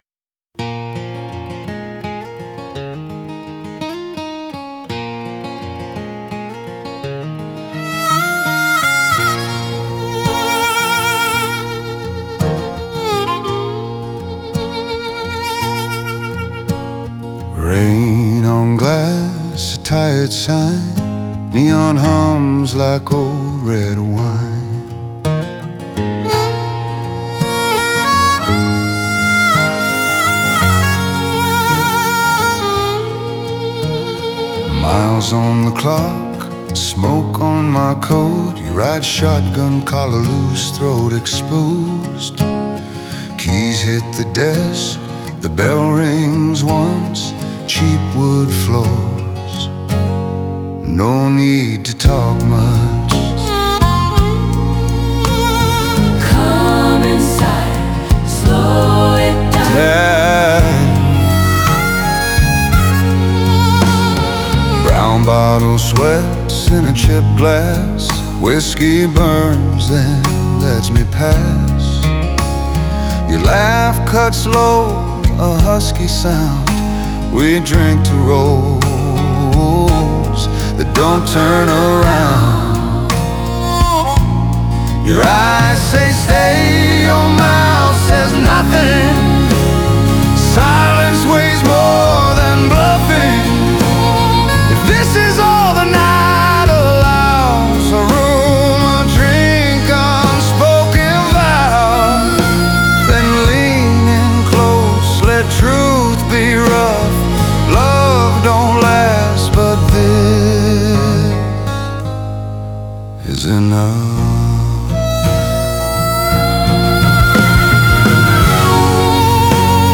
Inn At The Edge Of The Road(Acoustic Folk)